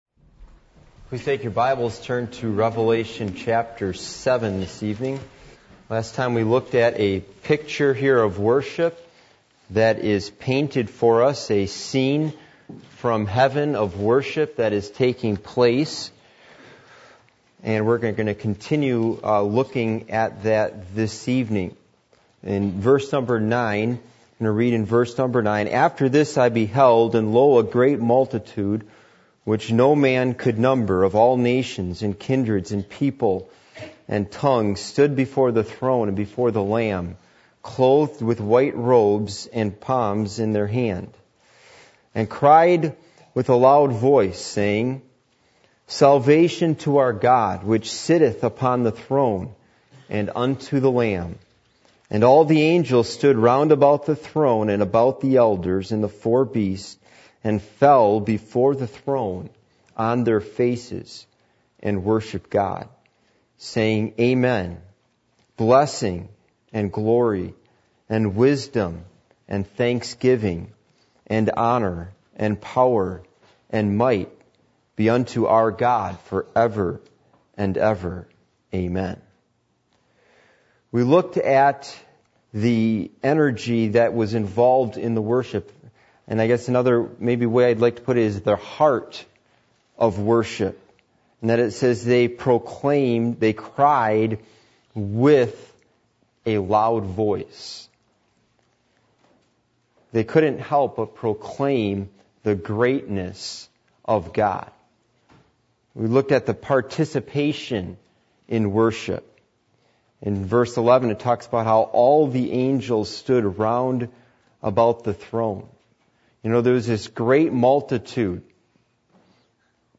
Passage: Revelation 7:9-12 Service Type: Midweek Meeting